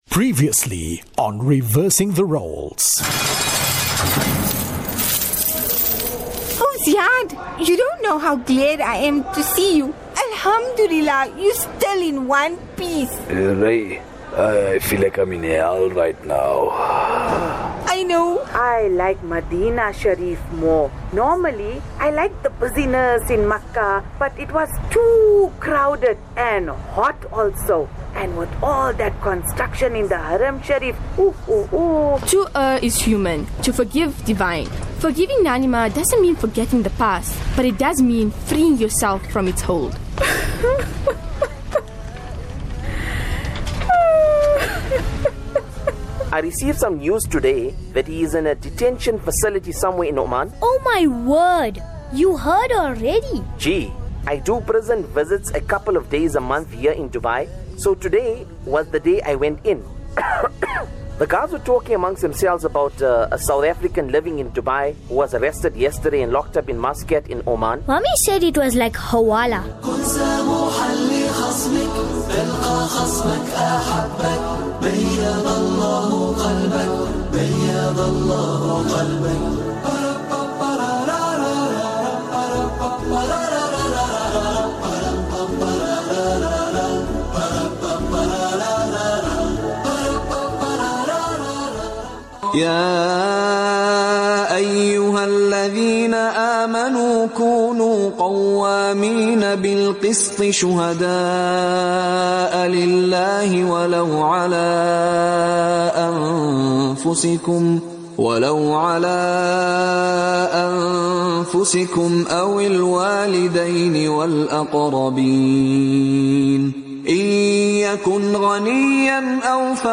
Drama 1446 – Reversing the Roles – Episode 15: The Case Against Ziyad | Radio Islam